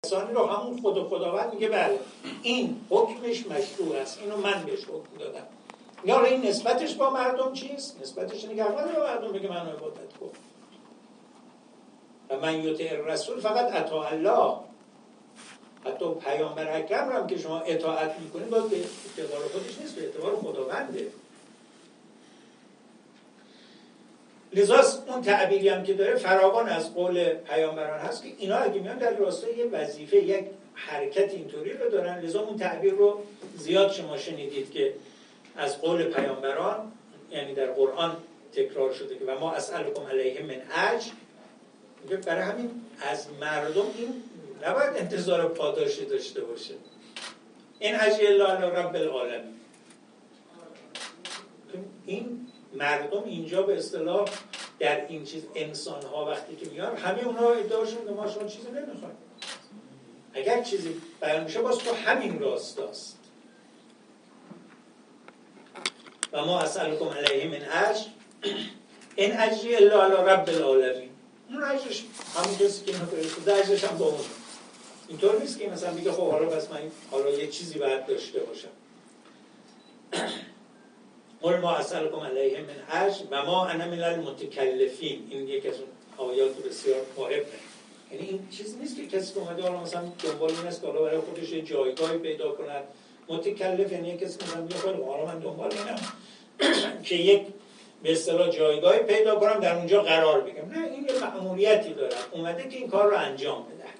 ایمان و عمل صالح دو مؤلفه خلیفةاللهی انسان بر زمین است + صوت و عکسبه گزارش خبرنگار ایکنا، نشستی از سلسله‌نشست‌های حکمت سیاسی اسلام در قرآن با سخنرانی سعید جلیلی؛  عضو مجمع تشخیص مصلحت نظام شامگاه سه‌شنبه، 5 دی‌ماه، در سالن طاهره صفارزاده حوزه هنری سازمان تبلیغات اسلامی برگزار شد.